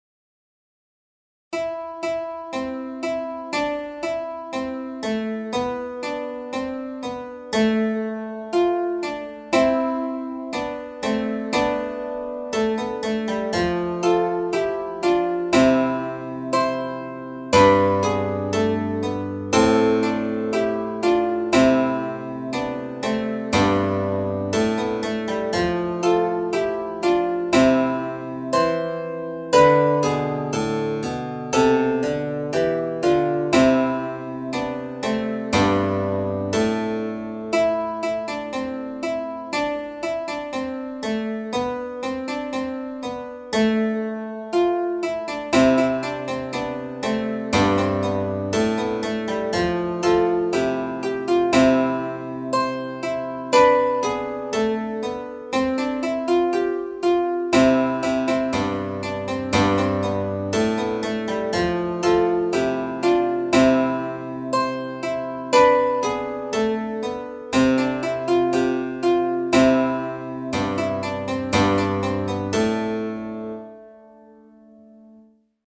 adding diminuitions in a- and g-, idiomatic to each key-